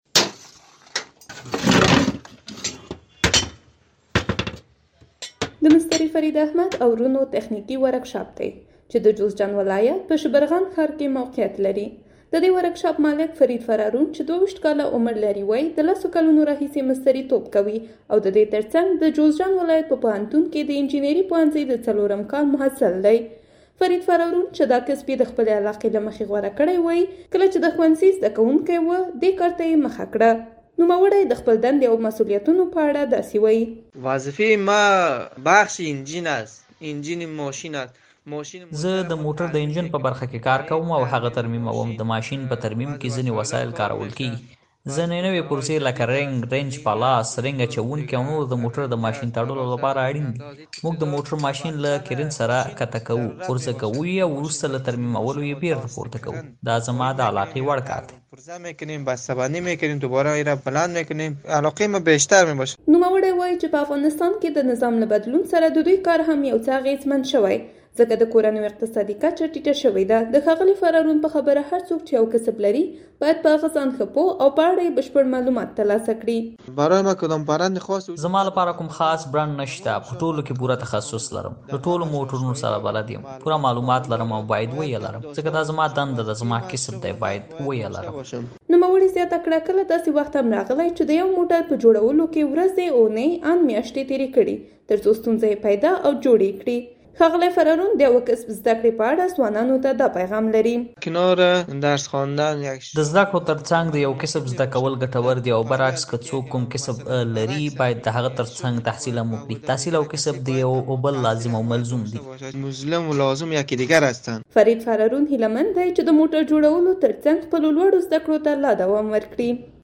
د مستري توب په اړه راپور